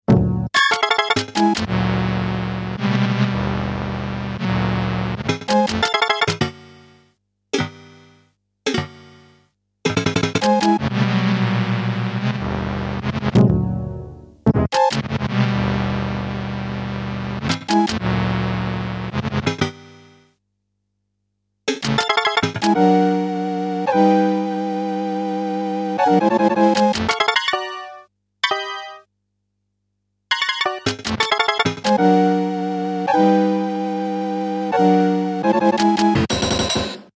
4 LFOtrigger active
It also happens that the shorting sometimes alters the timbre.